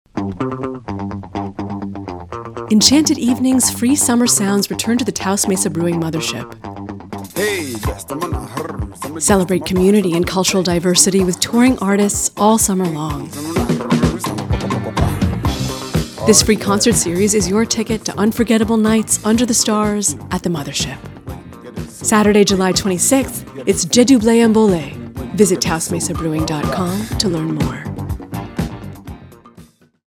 Commercial Voiceover Work